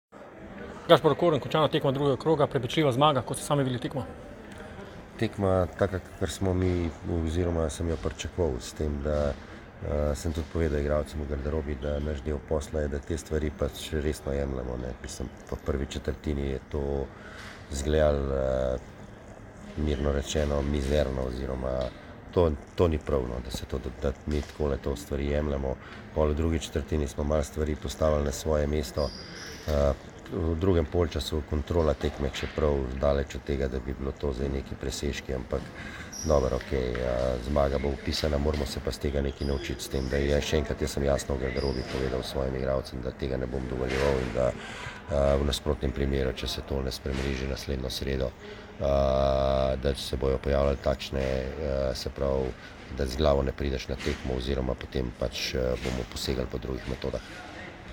Izjavi po tekmi: